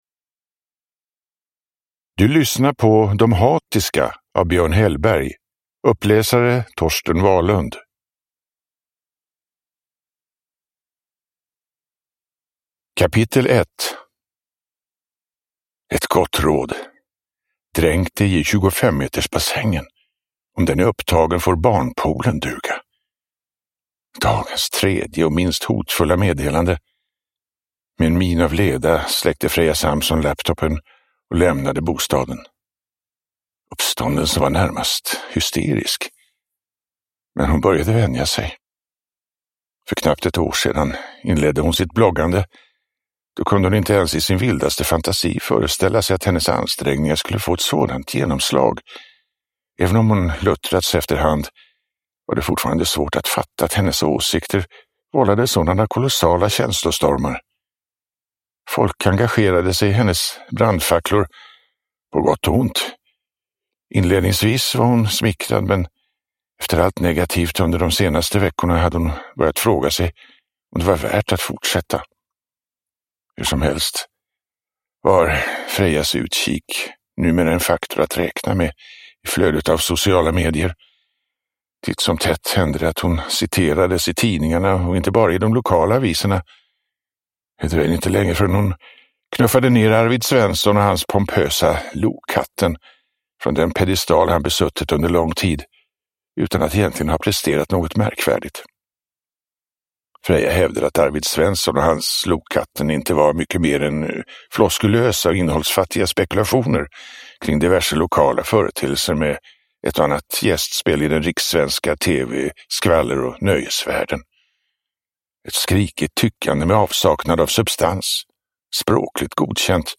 De hatiska – Ljudbok – Laddas ner
Uppläsare: Torsten Wahlund